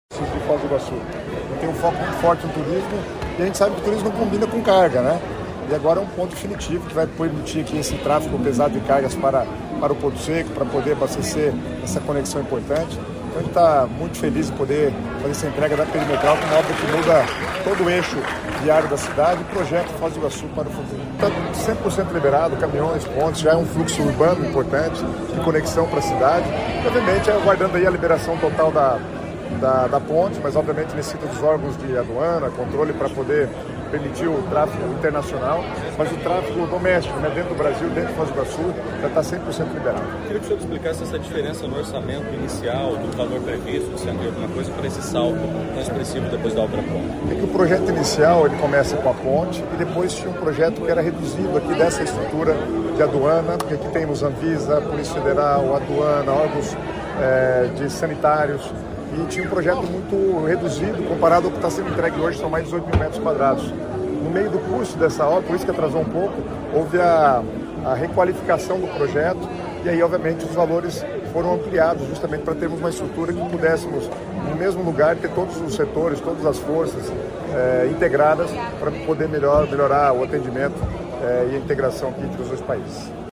Sonora do secretário Estadual das Cidades, Guto Silva, sobre a abertura da Perimetral Leste, em Foz